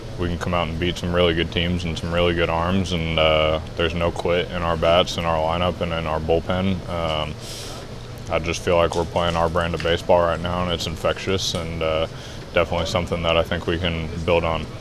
5. Cardinals starting pitcher Matthew Liberatore (LIBB-er-uh-tore) on what the team proved on a successful road trip